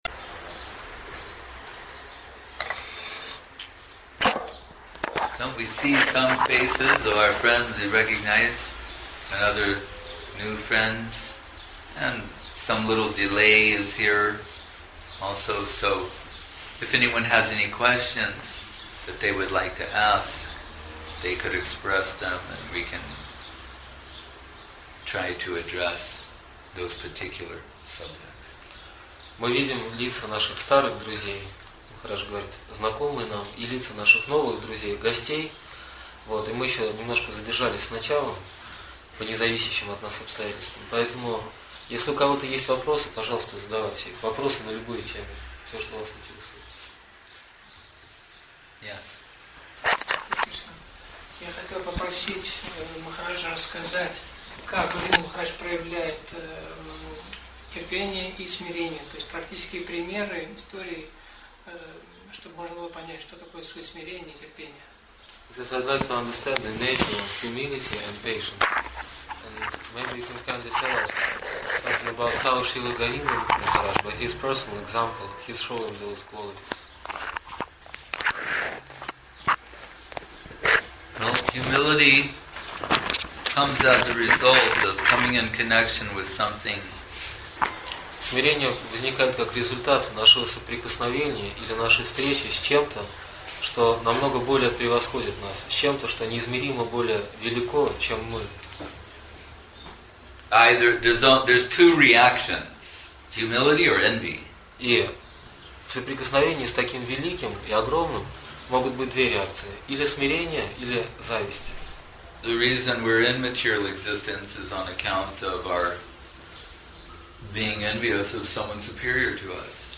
Place: Sri Chaitanya Saraswat Math Saint-Petersburg